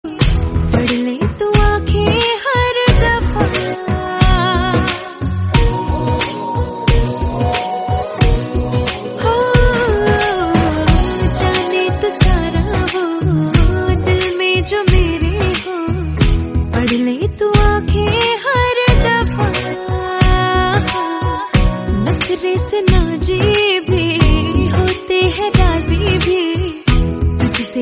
Category: Bollywood Ringtones